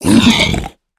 mob / piglin / death4.ogg